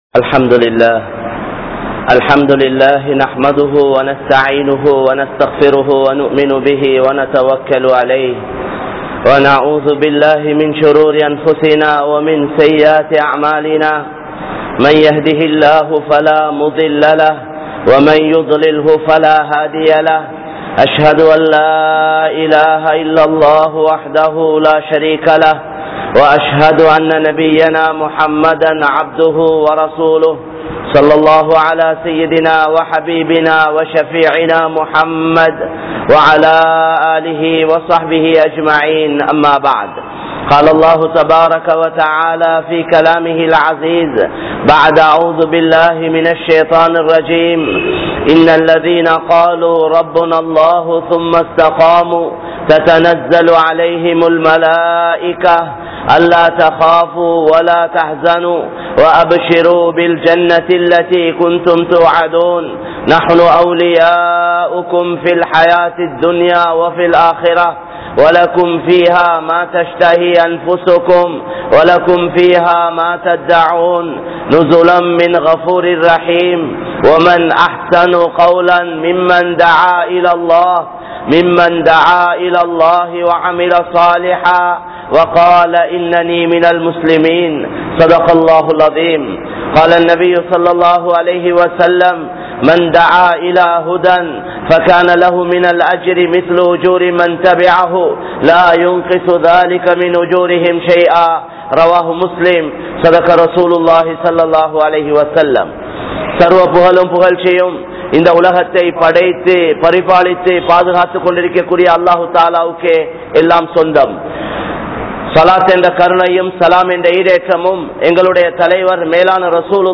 Manitharhalukkaaha Valaatheerhal (மனிதர்களுக்காக வாழாதீர்கள்) | Audio Bayans | All Ceylon Muslim Youth Community | Addalaichenai